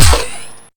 sfx_freeze_gun_WIP.wav